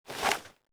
wpn_pistol10mm_equip.wav